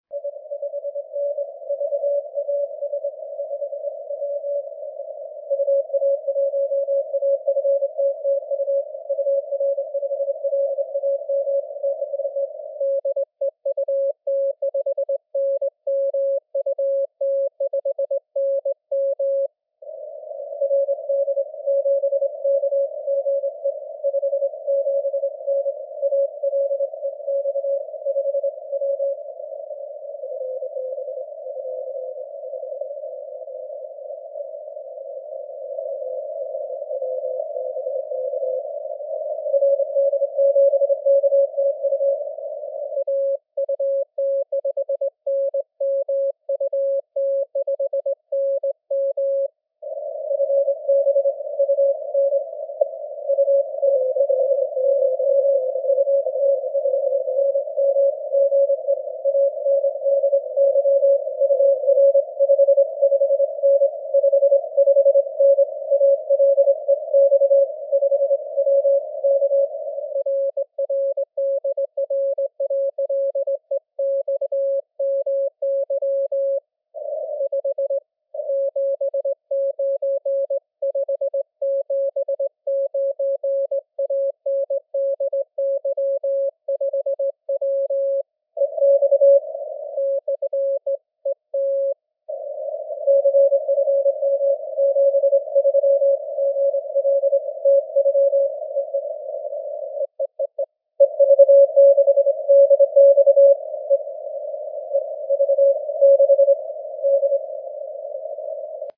Диапазоны тихие и достаточно чистые, всех было слышно от уровня шума(но разборчиво) до реальных 7 баллов по С-метру.